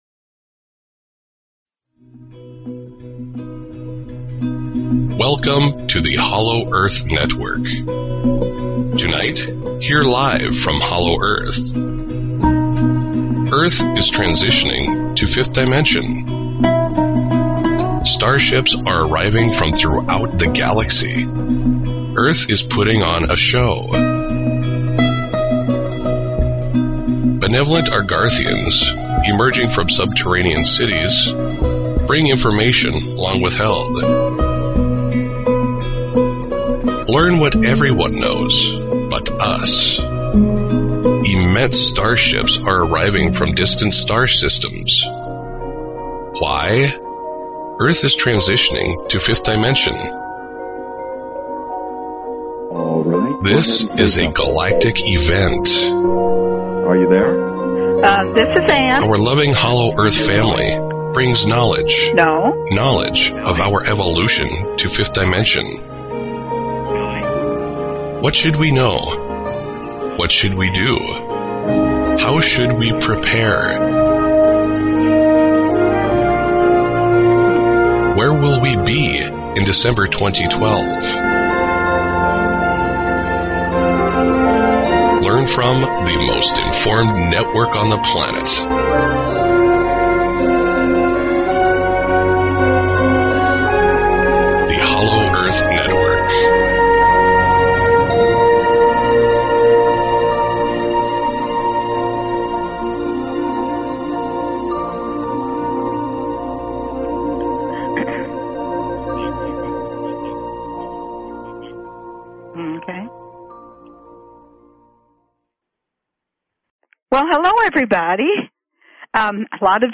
Talk Show Episode, Audio Podcast, Hollow_Earth_Network and Courtesy of BBS Radio on , show guests , about , categorized as